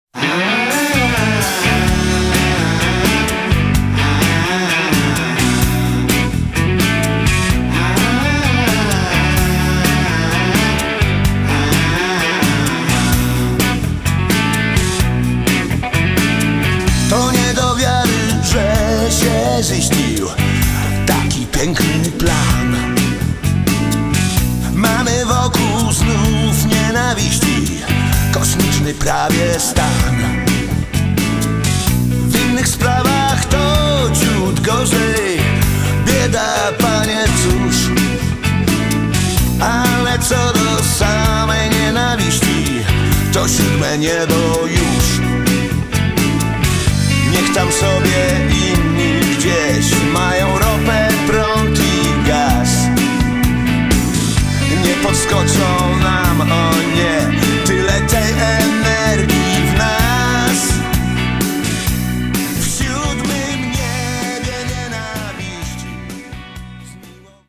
Notes Recorded at PAY , Warsaw
VOC GUITAR KEYB BASS DRUMS TEKST
zespół rockowy założony w 1981